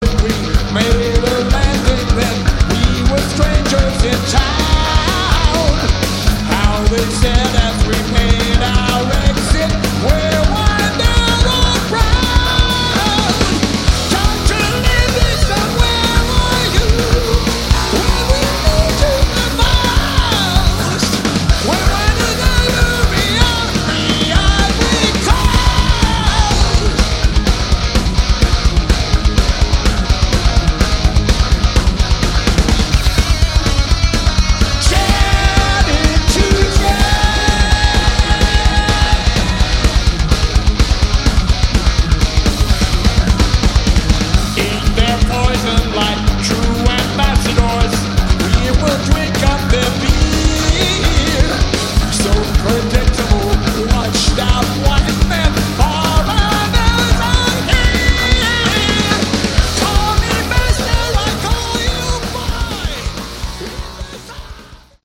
Category: Hard Rock
vocals
bass
guitar
drums